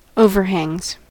overhangs: Wikimedia Commons US English Pronunciations
En-us-overhangs.WAV